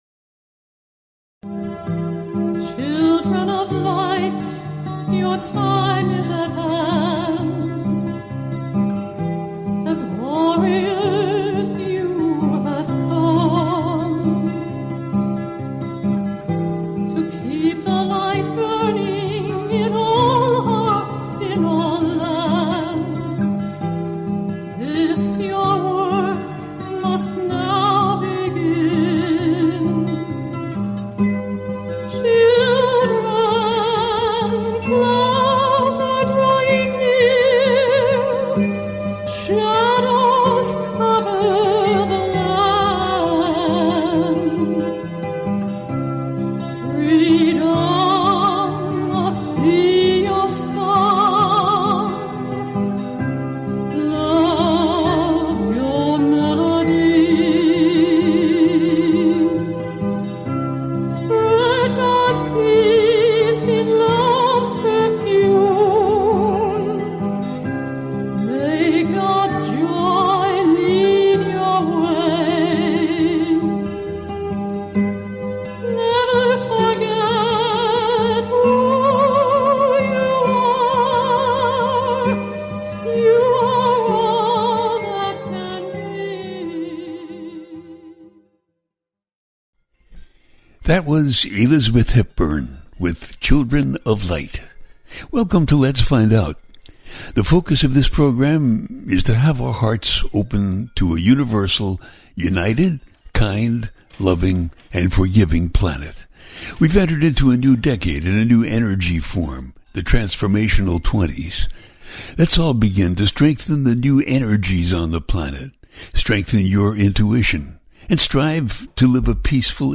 The listener can call in to ask a question on the air.
Each show ends with a guided meditation.